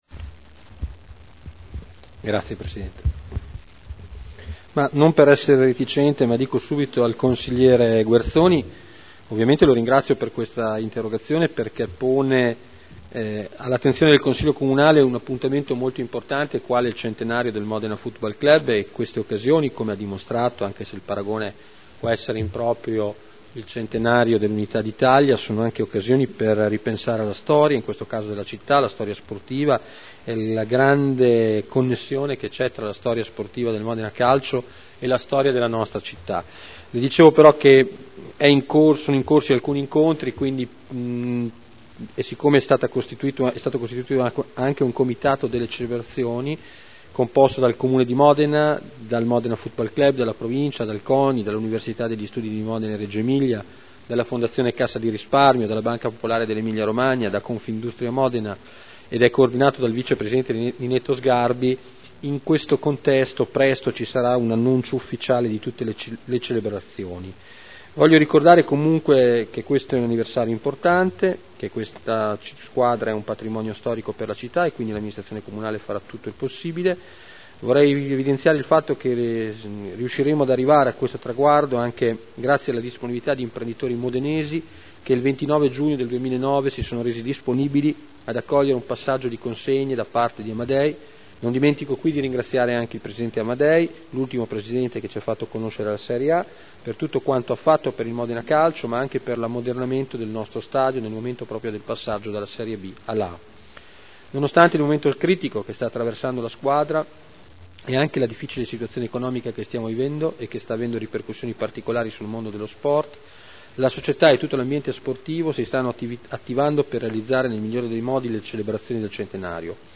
Seduta del 21 novembre Interrogazione dei consiglieri Prampolini e Guerzoni (P.D.) avente per oggetto: “Centenario Modena FC 1912” Risposta